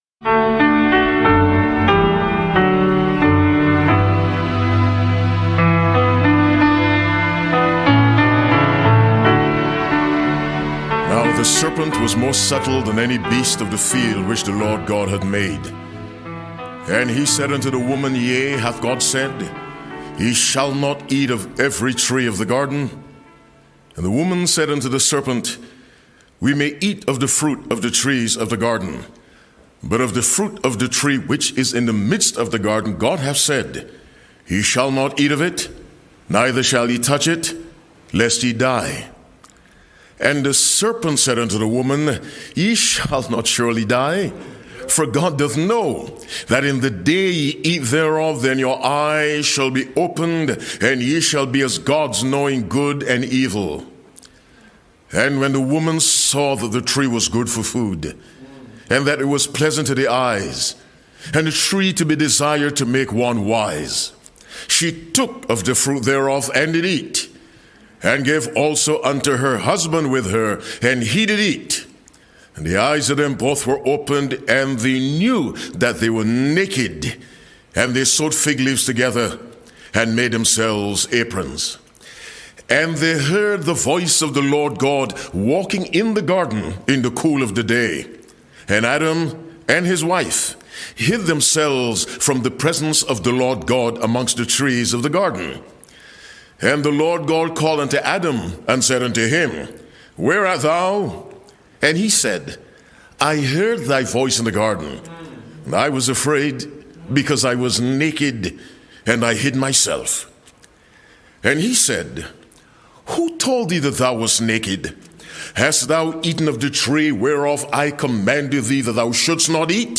Discover the subtlety of temptation, the depths of divine mercy, and the significance of the Book of Life in this powerful sermon.